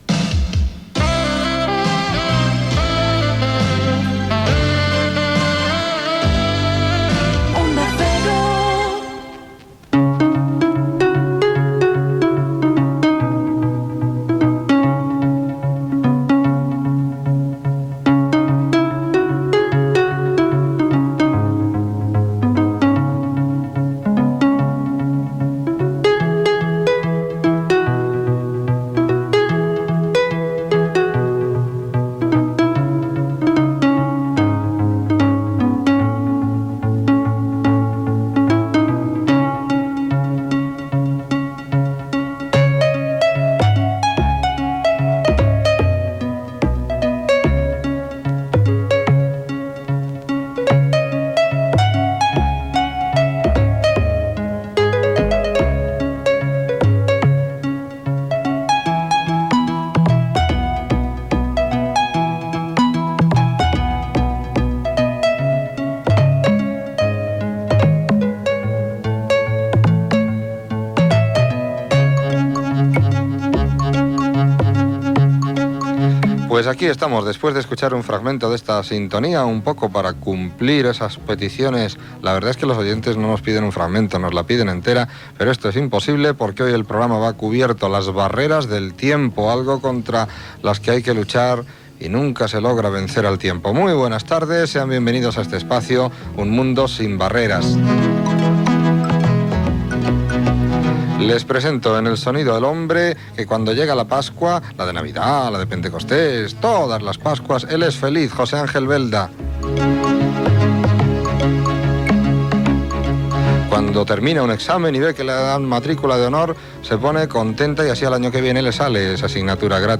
Indicatiu de l'emissora, sintonia del programa, presentació, equip, publicitat
Divulgació